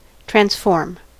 transform: Wikimedia Commons US English Pronunciations
En-us-transform.WAV